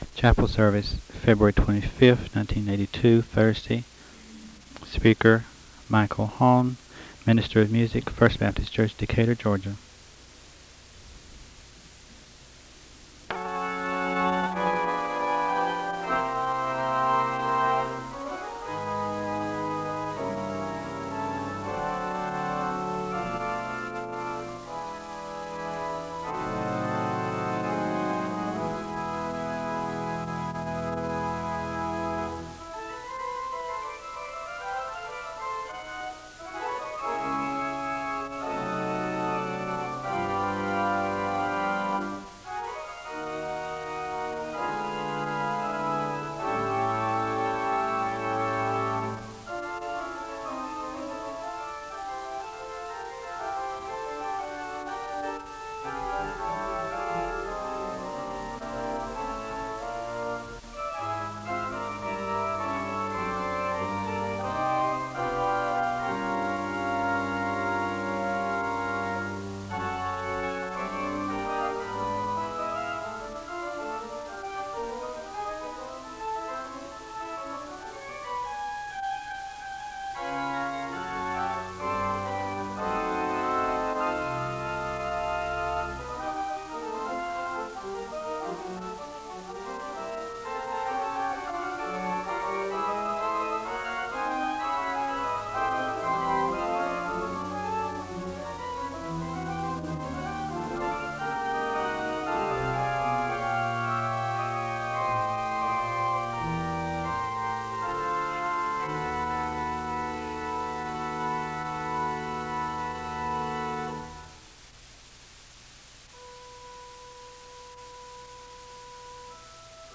The service begins with organ music (00:00-04:05). The speaker gives a word of prayer (04:06-08:10).
SEBTS Chapel and Special Event Recordings SEBTS Chapel and Special Event Recordings